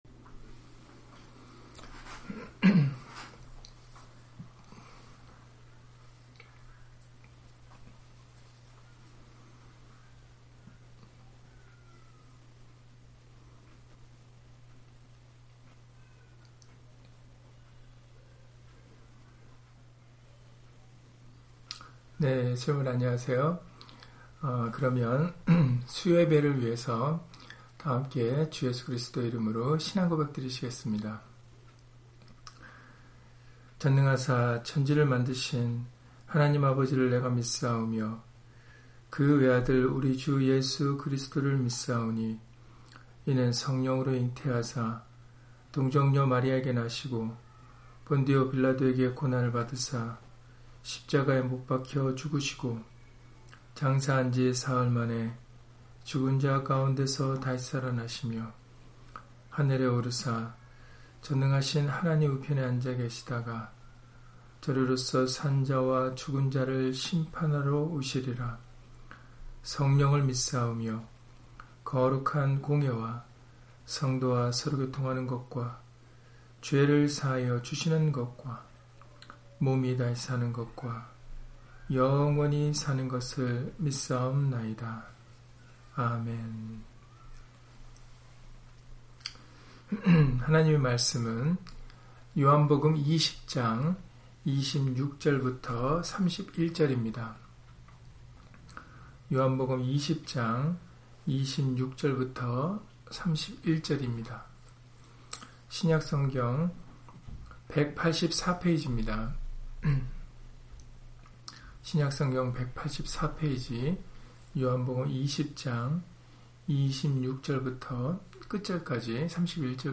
요한복음 20장 26-31절 [그 이름을 힘입어 생명을 얻게 하려 함] - 주일/수요예배 설교 - 주 예수 그리스도 이름 예배당